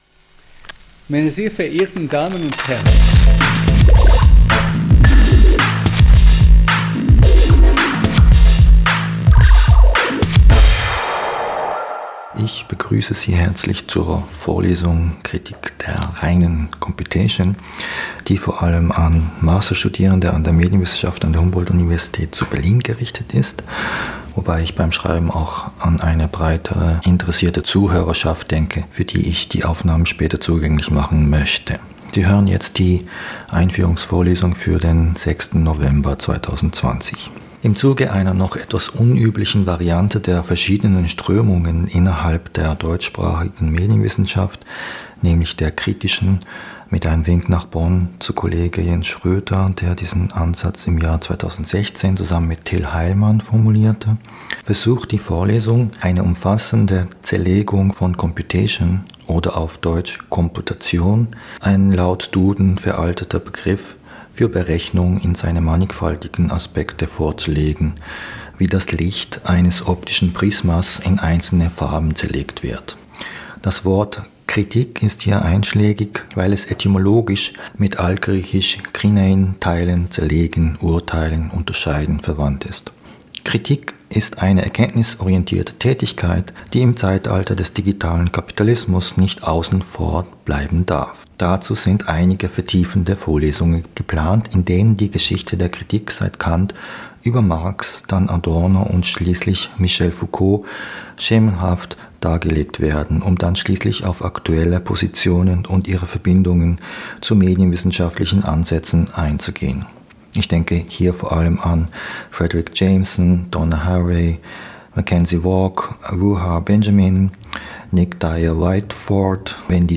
Podcastvorlesungen